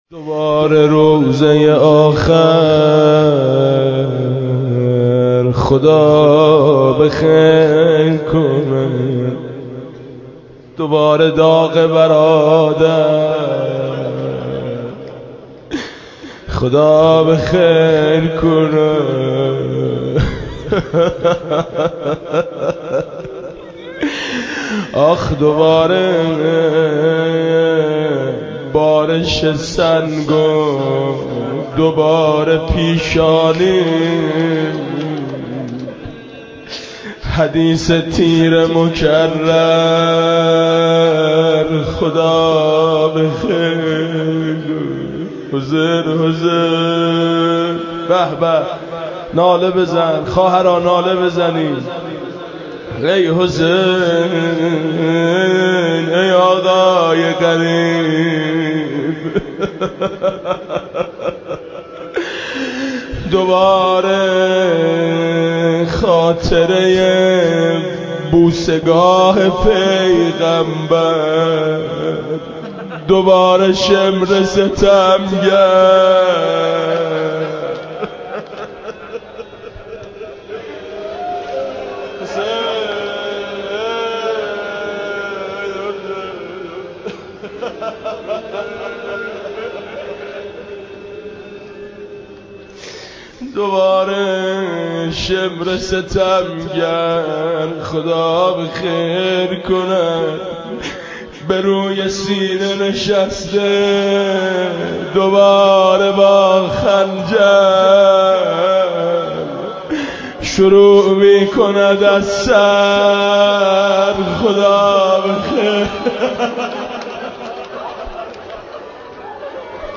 عصر عاشورا
1 دوباره روضه آخر خدا به خیر کند (روضه)